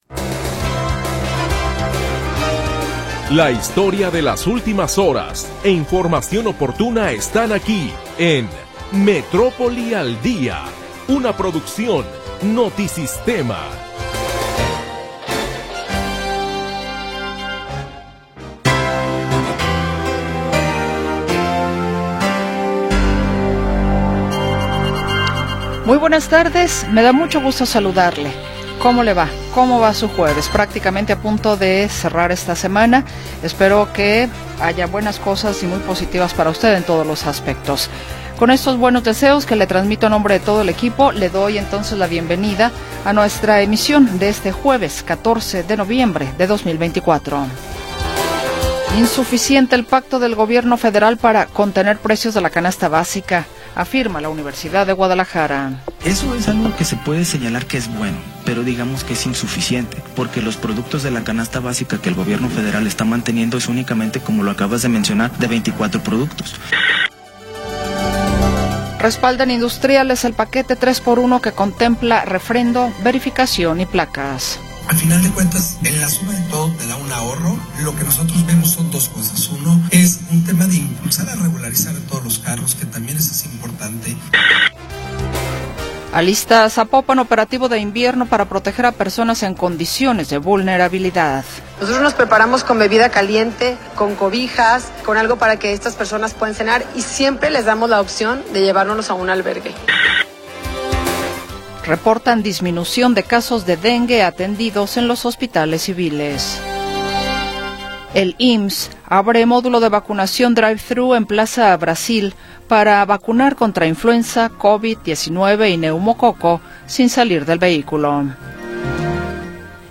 1 Metrópoli al Día 2da Hora - 14 de Noviembre de 2024 47:22 Play Pause 6h ago 47:22 Play Pause Afspil senere Afspil senere Lister Like Liked 47:22 La historia de las últimas horas y la información del momento. Análisis, comentarios y entrevistas